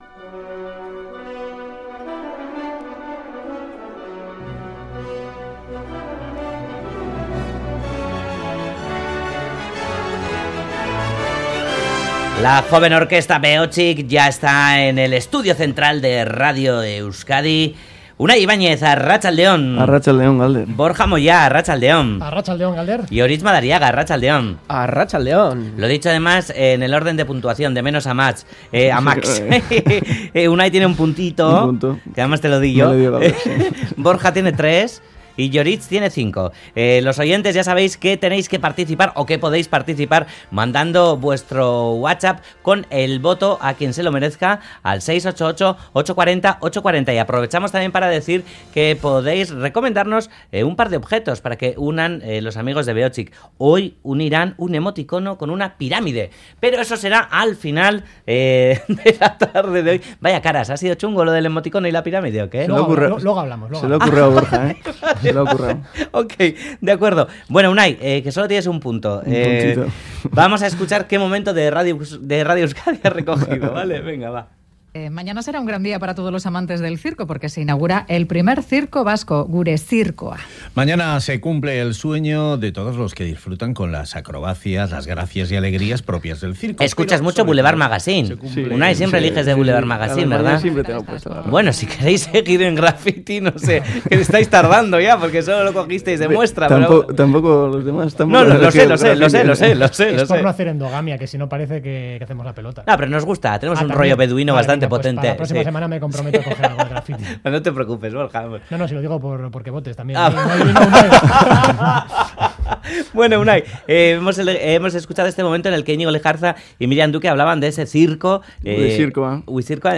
Audio: La joven orquesta Behotsik ha escogido tres momentos del Boulevard de Radio Euskadi para ponerles banda sonora en su espacio semanal de Graffiti.